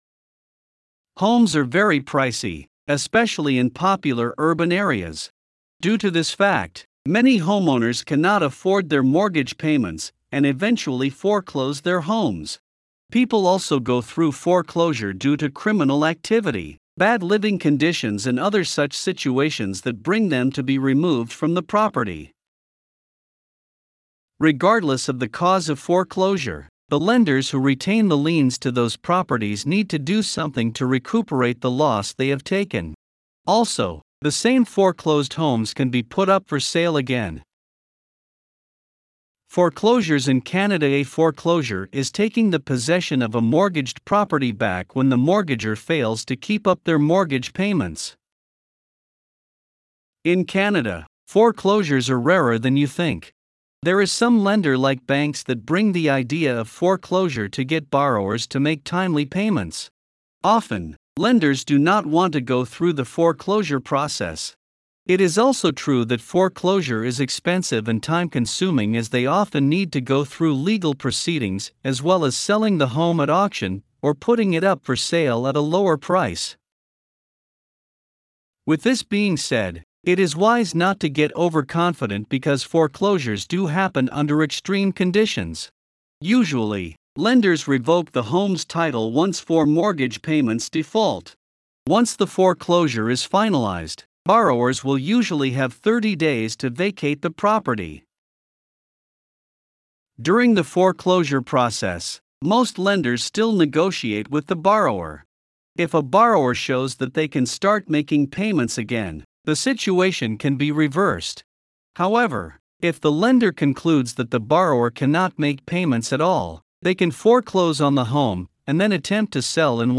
Voiceovers-Voices-by-Listnr_2.mp3